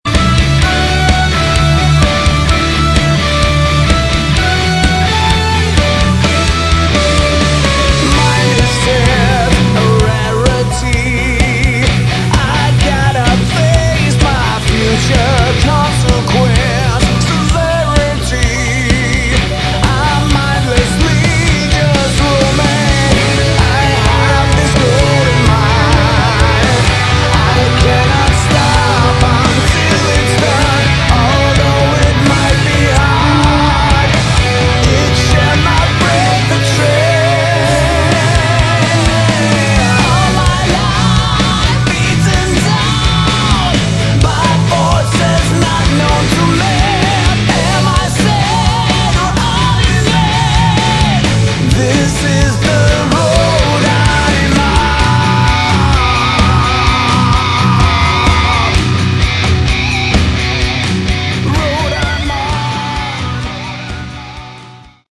Category: Melodic Rock / AOR
guitar, bass, synthesizer, backing vocals
drums, percussion
keyboards